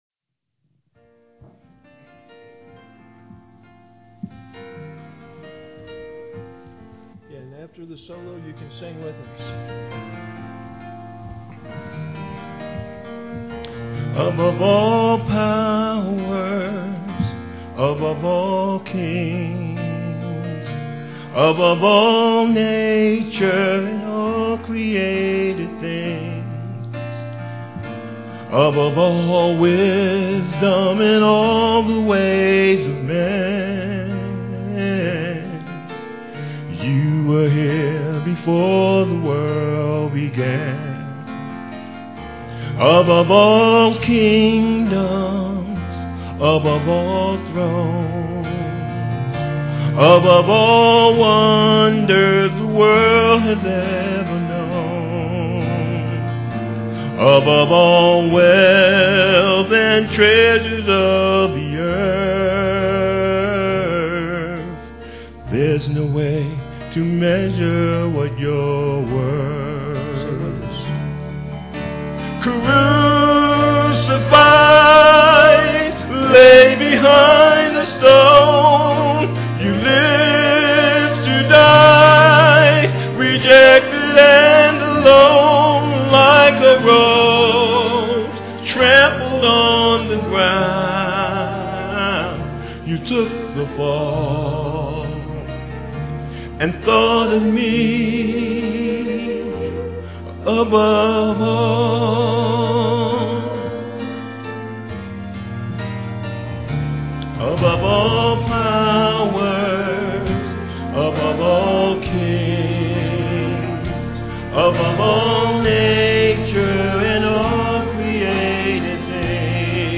Vocal duet
piano accompaniment
Guitar and vocal solos